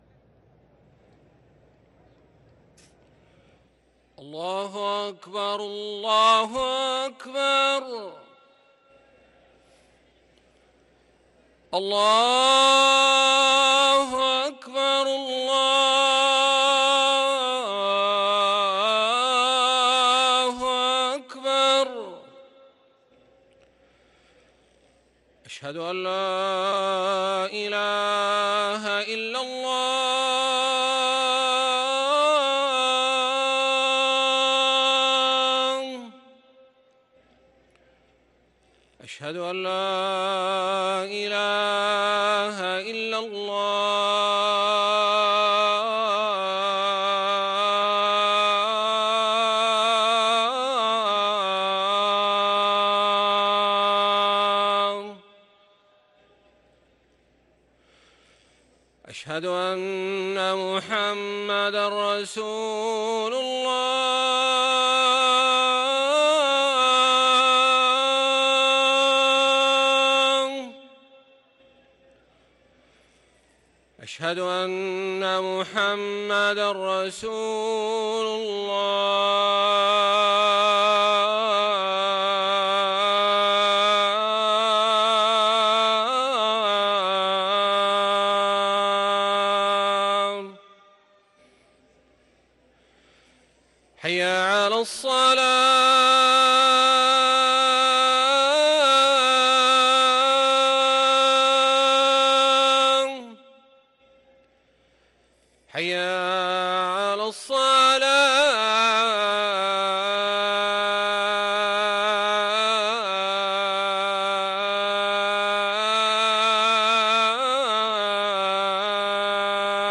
أذان الجمعة الأول
ركن الأذان